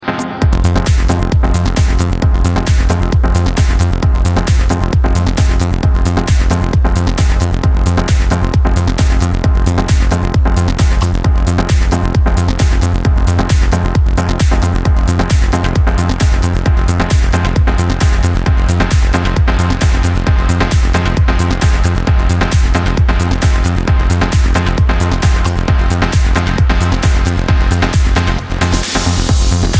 Список файлов рубрики ~* Trance *~ Файл 16.mp3 1 из 9 » ...